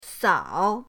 sao3.mp3